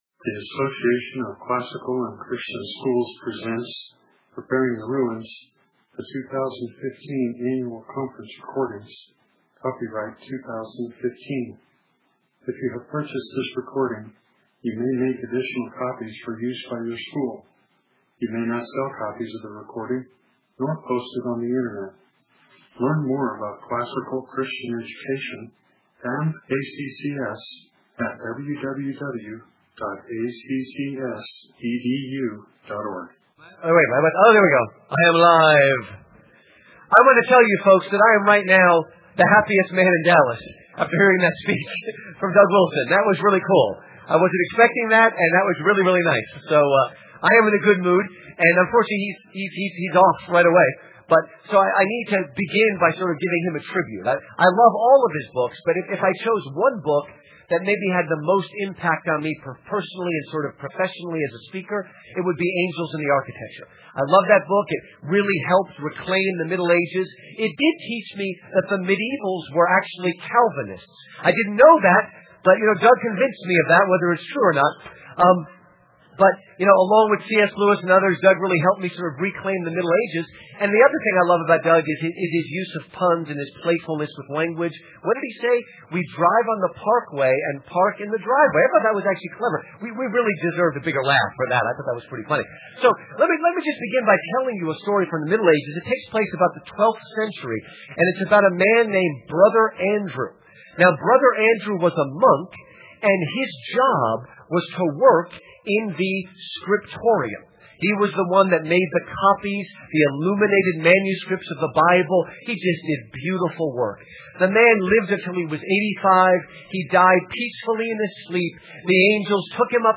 2015 Workshop Talk | 1:17:54 | 7-12, K-6, History